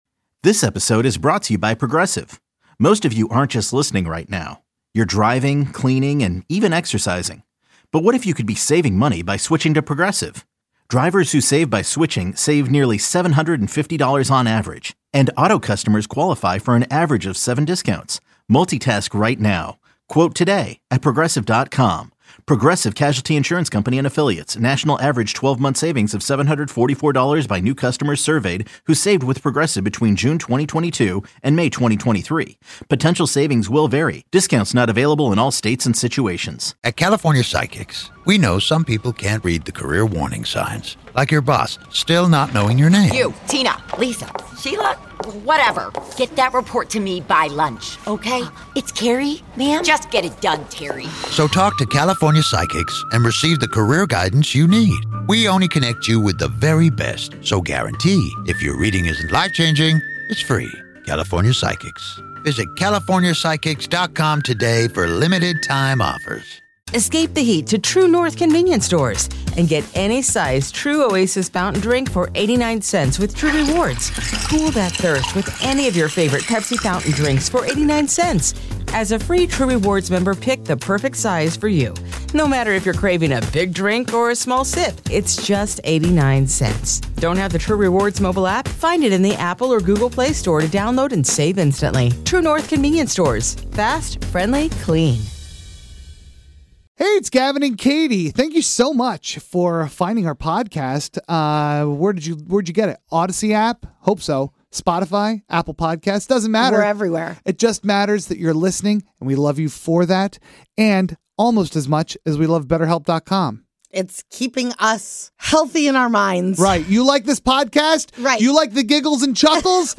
The world's okayest morning radio show offers you the entire broadcast from today with none of the music and limited commercials.